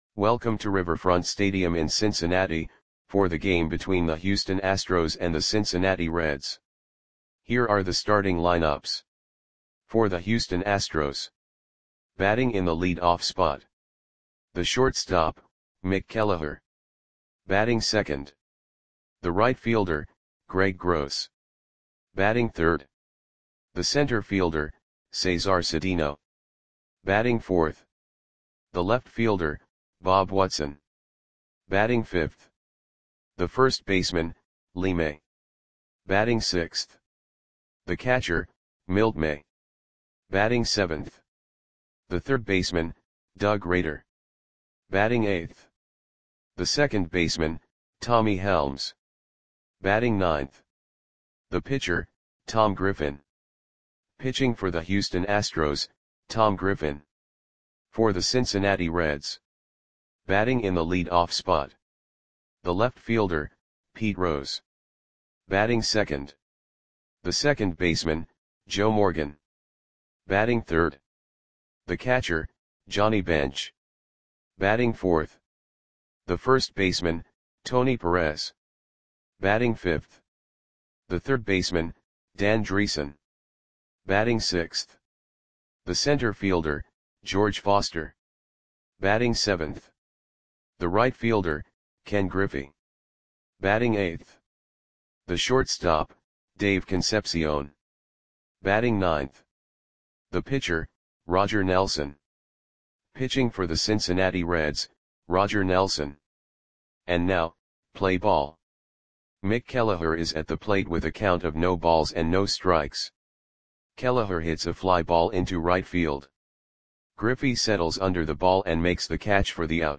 Click the button below to listen to the audio play-by-play.
Astros 4 @ Reds 2 Riverfront StadiumMay 11, 1974 (No Comments)